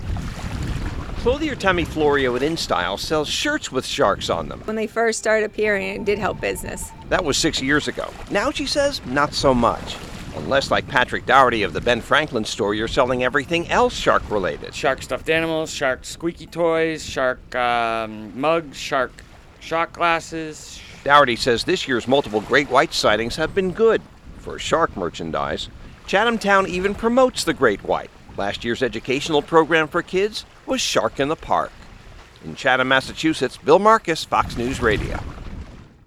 FOX NEWS RADIO’S